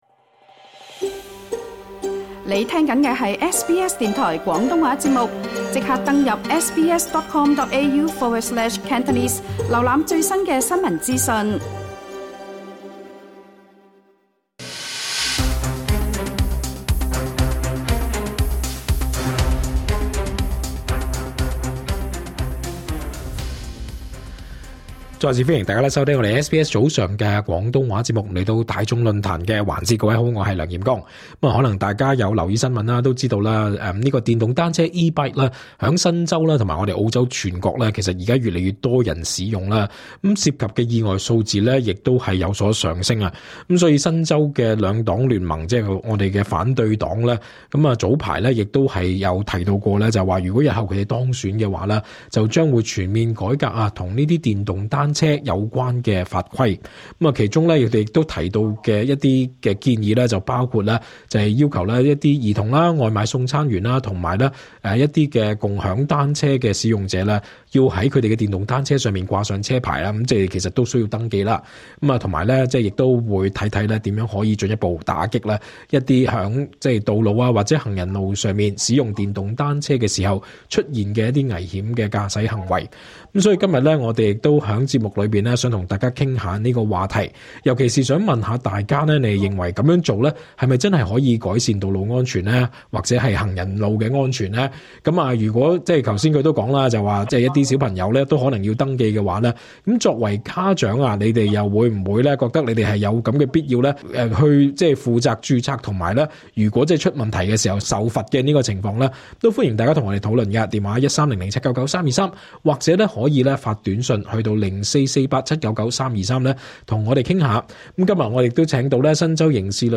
節目期間有聽眾支持為電動單車掛牌的方案，完整內容請聽足本節目錄音。